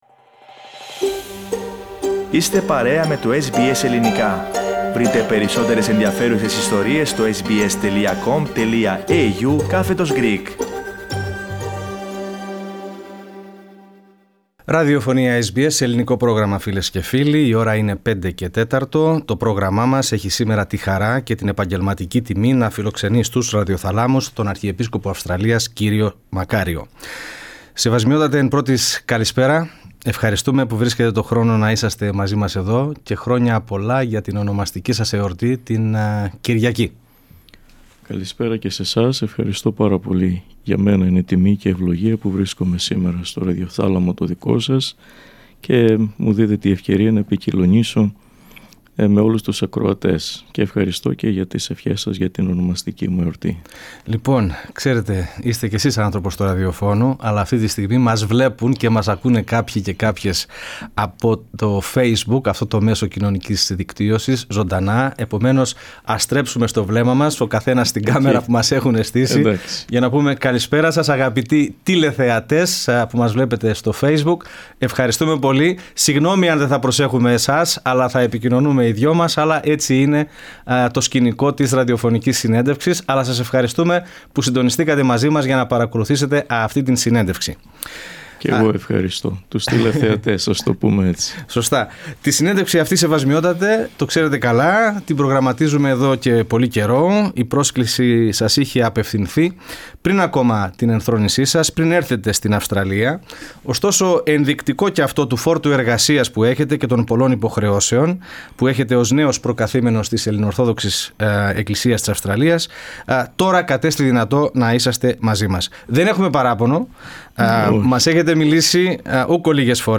Τη σχετική δήλωση έκανε σε αποκλειστική συνέντευξη, που παραχώρησε στο Ελληνικό Πρόγραμμα της SBS